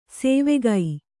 ♪ sēvegai